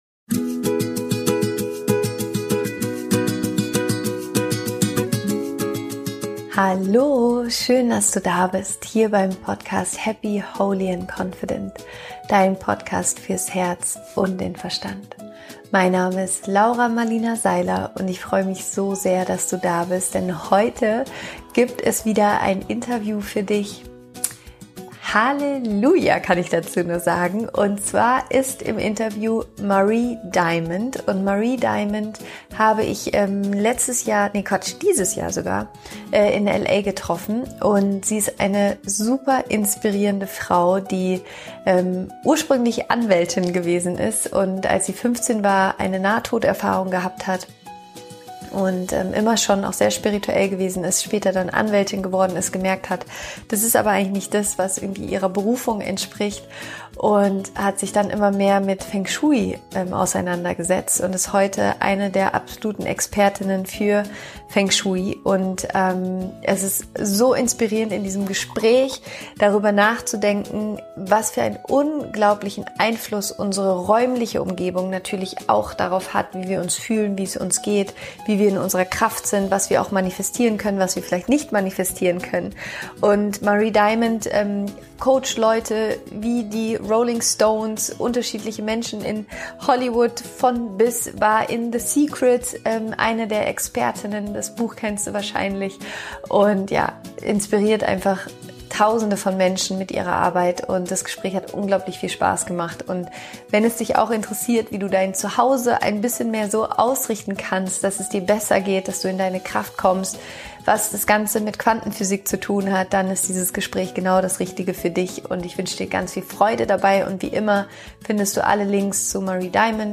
Wie dir deine Umgebung hilft, deine Ziele zu erreichen – Interview Special